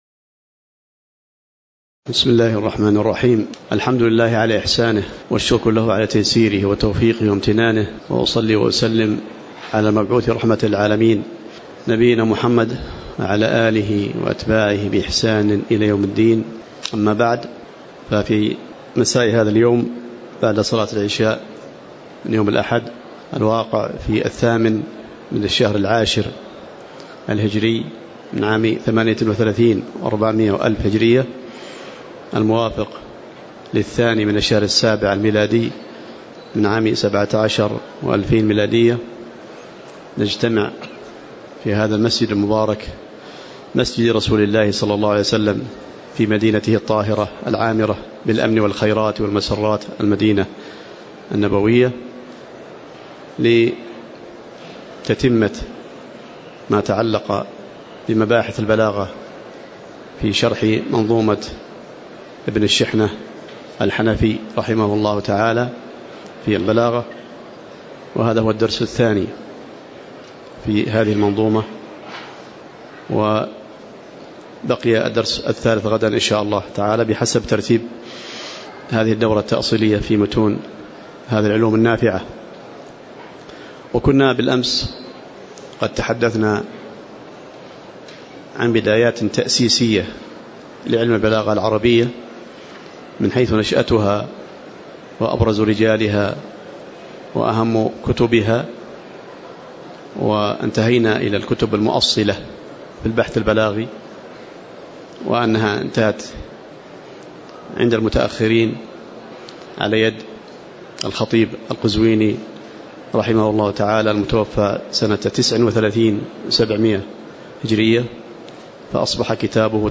تاريخ النشر ٨ شوال ١٤٣٨ هـ المكان: المسجد النبوي الشيخ